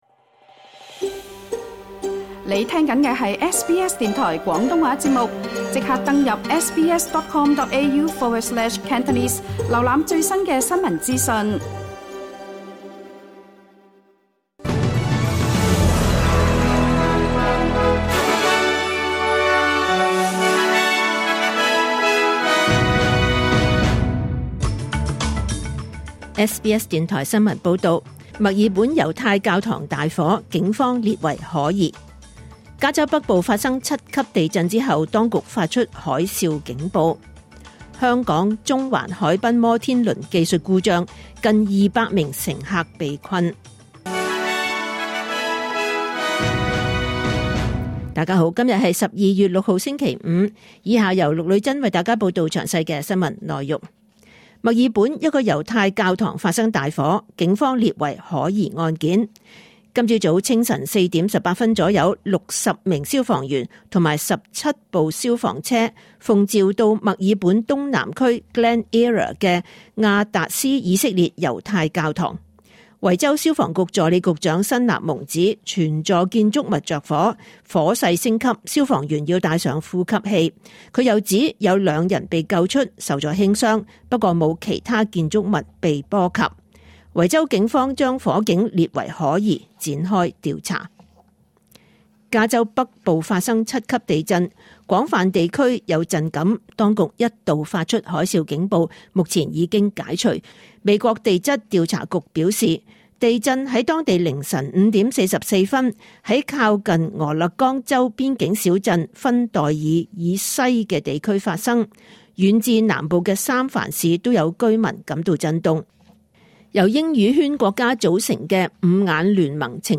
2024 年 12 月 6 日 SBS 廣東話節目詳盡早晨新聞報道。